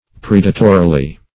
predatorily - definition of predatorily - synonyms, pronunciation, spelling from Free Dictionary Search Result for " predatorily" : The Collaborative International Dictionary of English v.0.48: Predatorily \Pred"a*to*ri*ly\, adv. In a predatory manner.